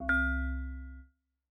steeltonguedrum_c1.ogg